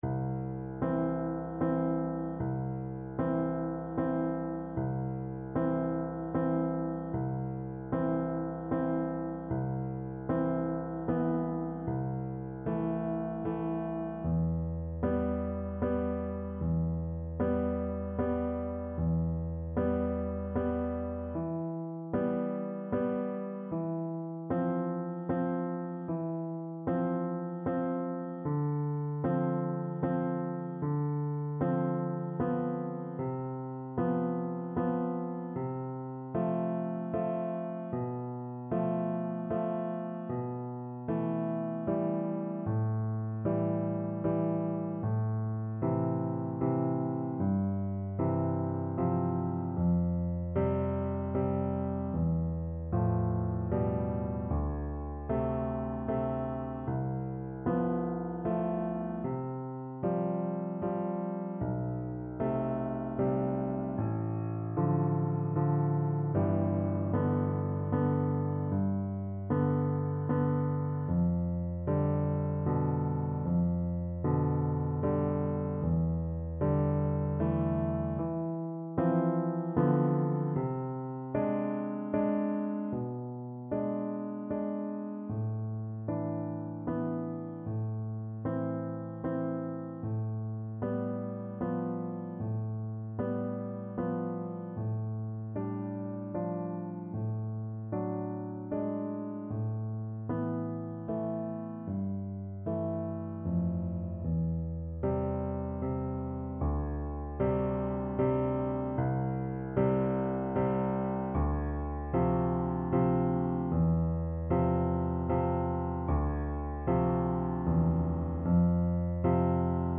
Adagio assai =76
3/4 (View more 3/4 Music)
Classical (View more Classical Flute Music)